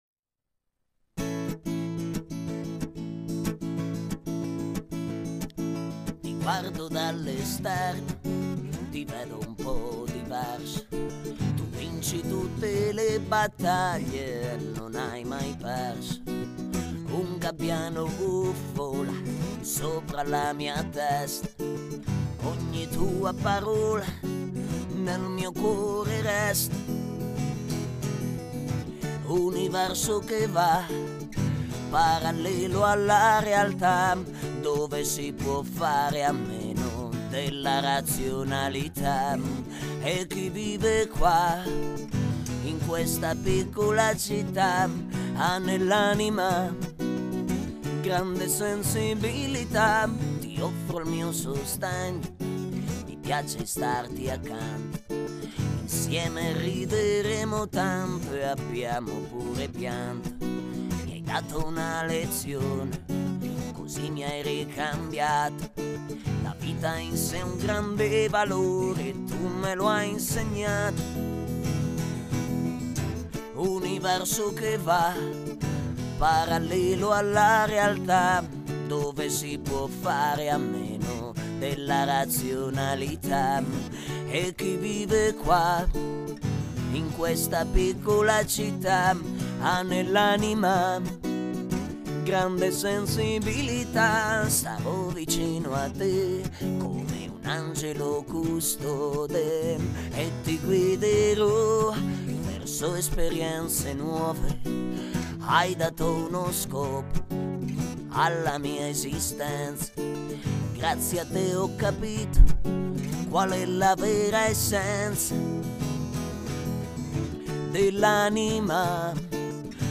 Genere: Clip musicale video clip